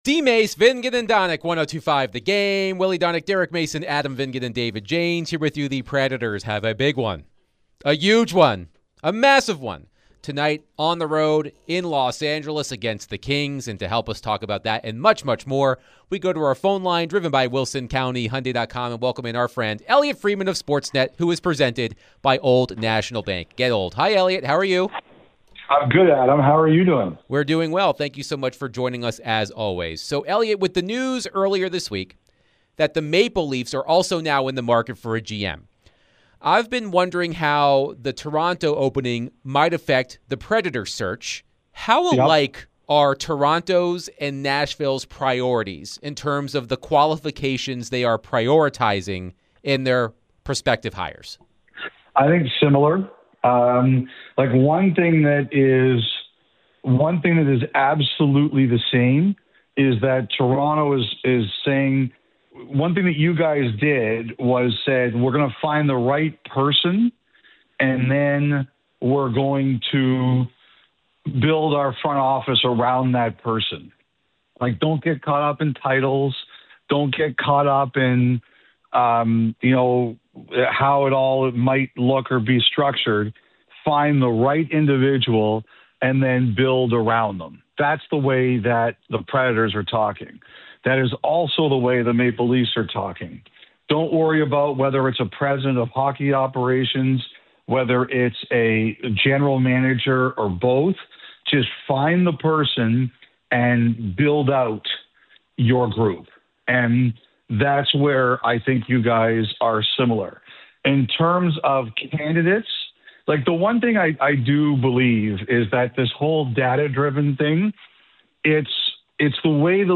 NHL Insider Elliotte Friedman joins DVD to discuss the NHL, Preds, the New GM Search for both Preds and Toronto, and much more.